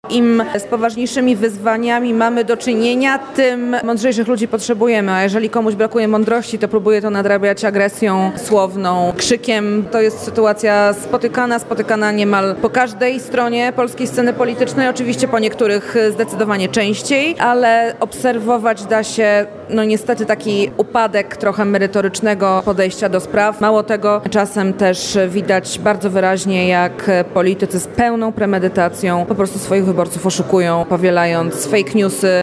Dużym zainteresowaniem cieszyła się kolejna konferencja Centrum Dialogu, która odbyła się w Grabanowie koło Białej Podlaskiej.